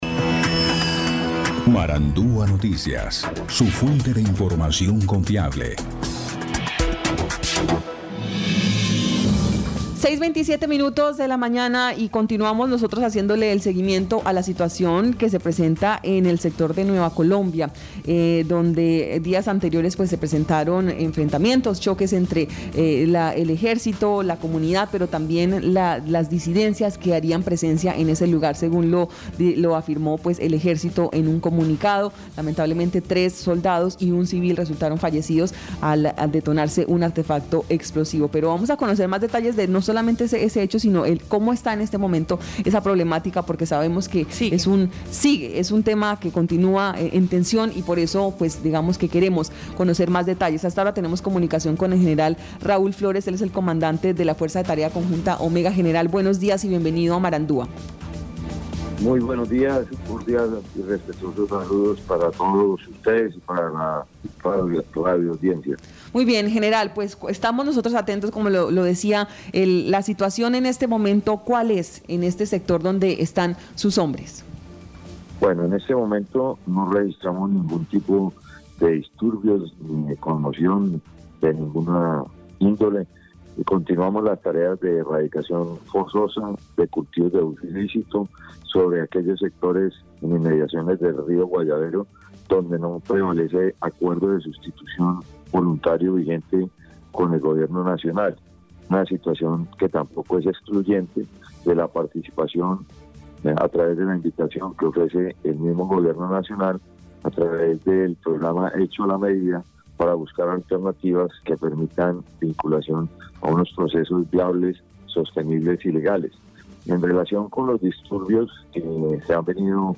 Escuche al Brigadier General Raul Flórez, comandante de la Fuerza de Tarea Conjunta Omega.